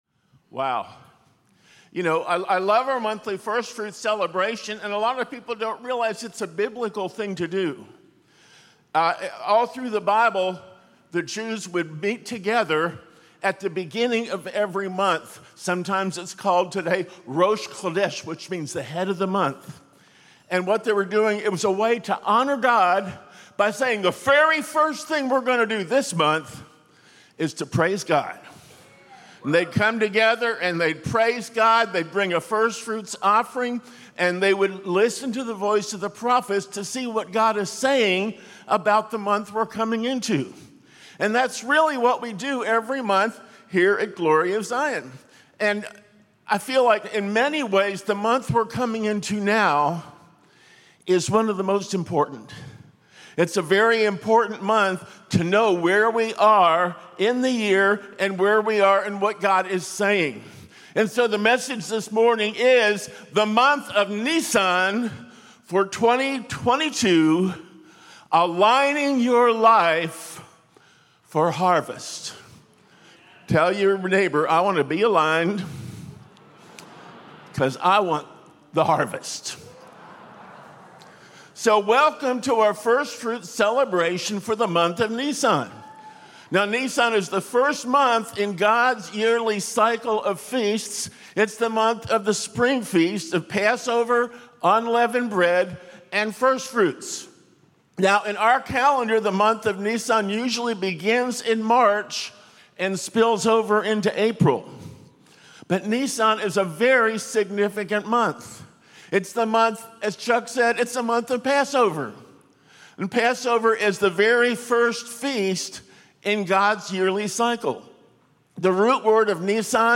Sunday Celebration Service